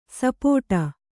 ♪ sapōṭa